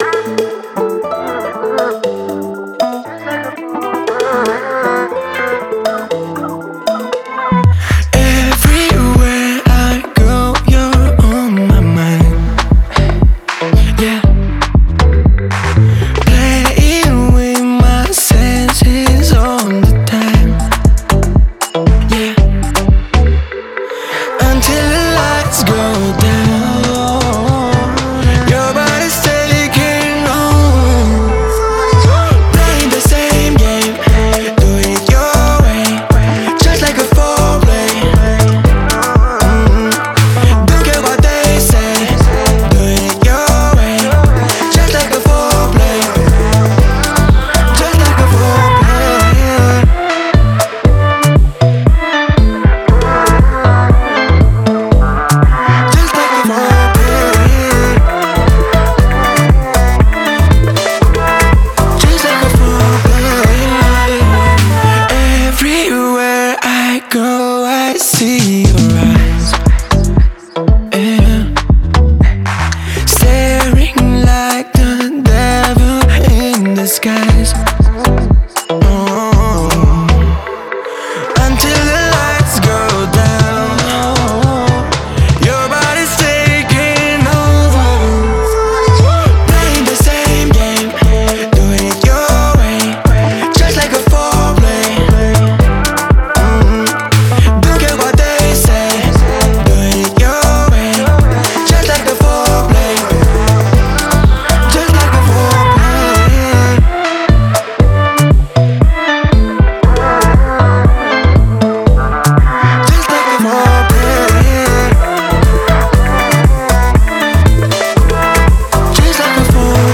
это яркая и энергичная композиция в жанре поп